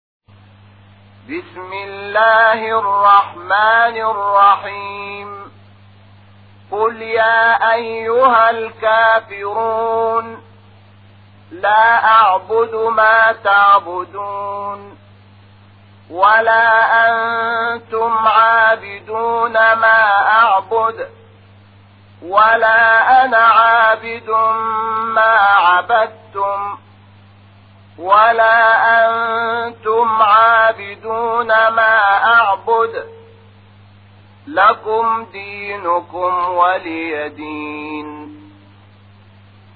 فایل صوتی سوره کافرون محمود شحات انور